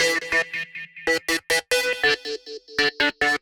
Index of /musicradar/future-rave-samples/140bpm
FR_RaveSquirrel_140-E.wav